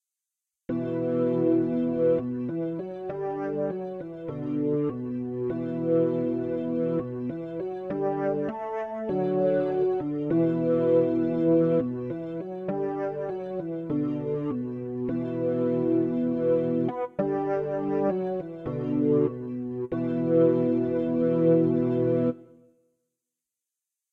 New tune, apologies in advance for abusive chording.
slightly_epic.ogg